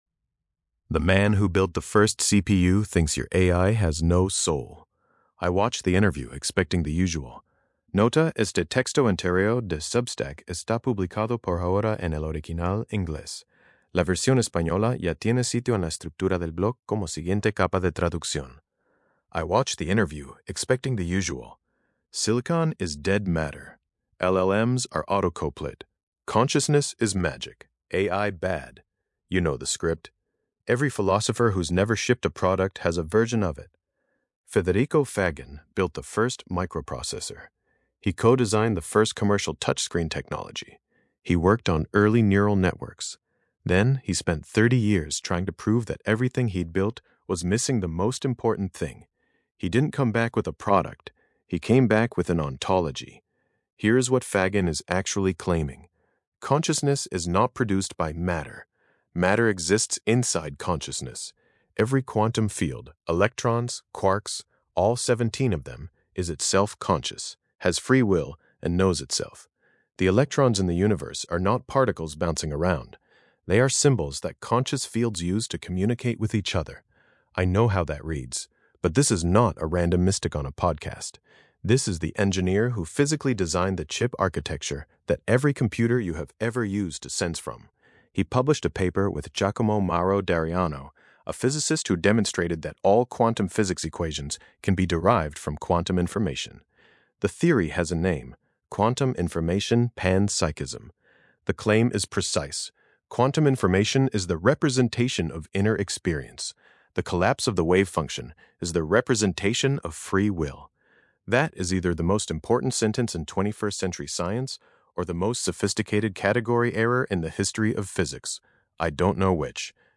Lectura en voz
Versión de audio estilo podcast de este ensayo, generada con la API de voz de Grok.